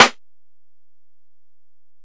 Snare (53).wav